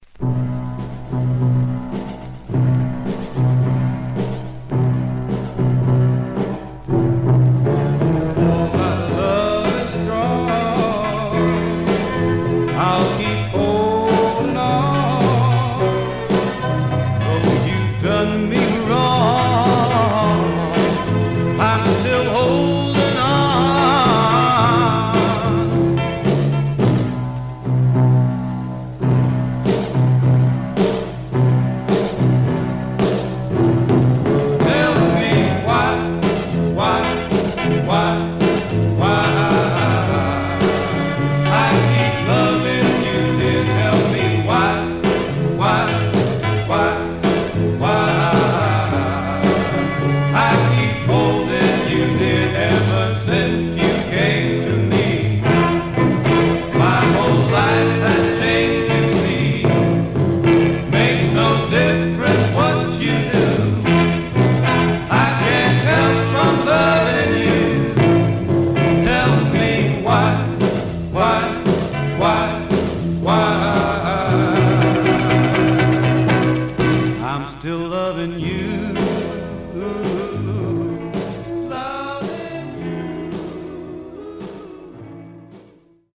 Stereo/mono Mono
Soul